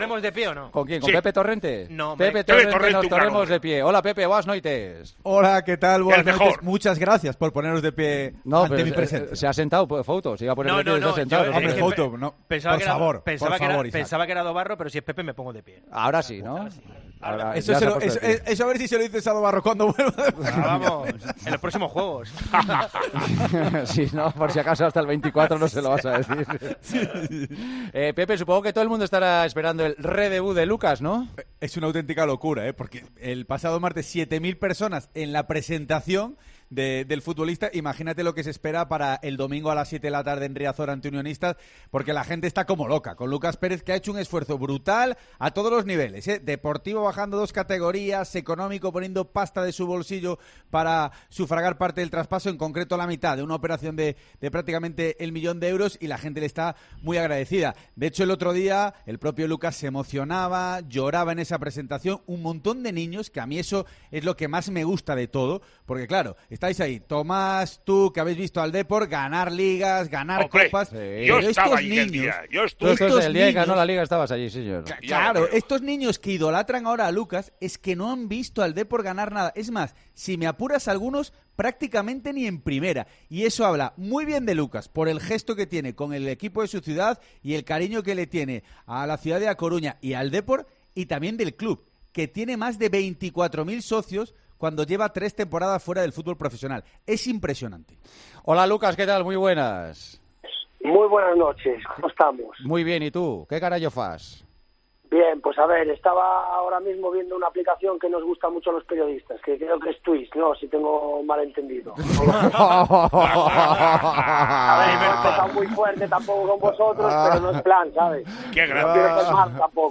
El delantero se pasó este jueves por El Partidazo de COPE y ha explicado el por qué cambia un equipo de Primera División como es el Cádiz por el Dépor que milita ahora mismo en 1ª RFEF: "Lo que un gaditano siente por el Cádiz, es lo que siente un coruñés por el Dépor".